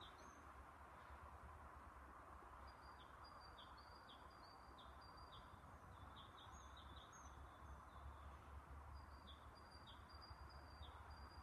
большая синица, Parus major
Administratīvā teritorijaStrenču novads
СтатусСлышен голос, крики